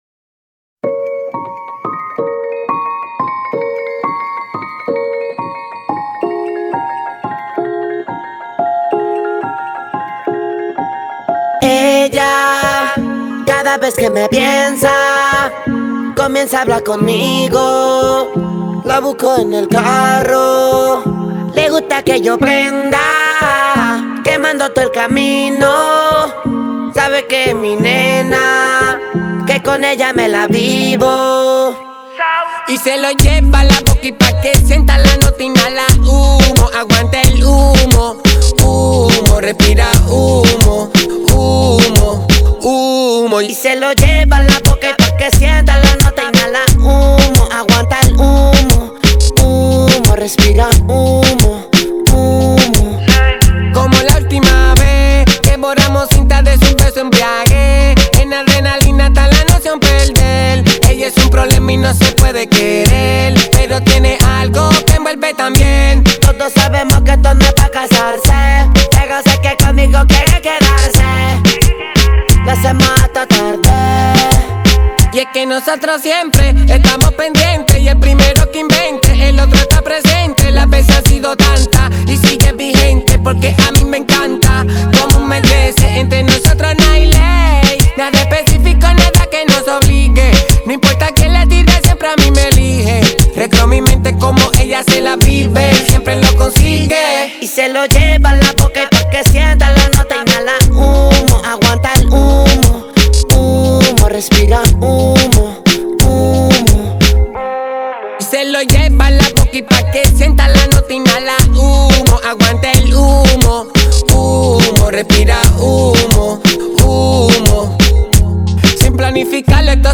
Carpeta: Reggaeton y + mp3